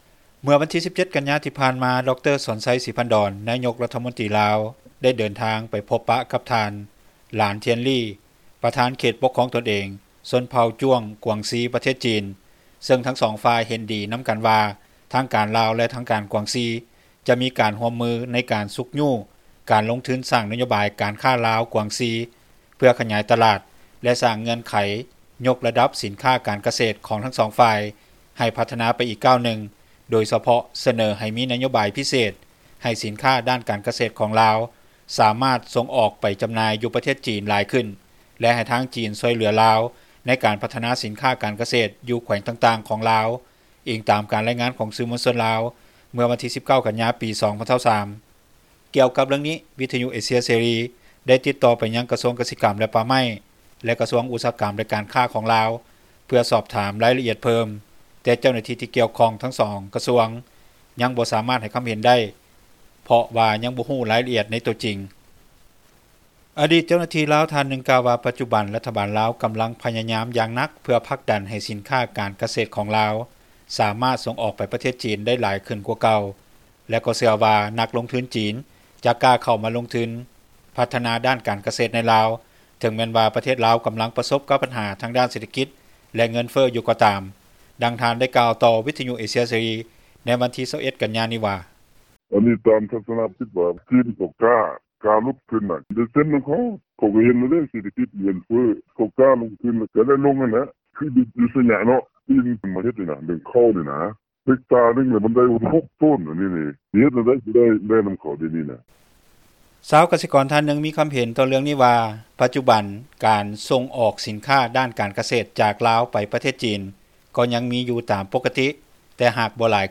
ດັ່ງຜູ້ຜລິດ ດ້ານກະເສດອິນຊີ ນາງນຶ່ງກ່າວວ່າ: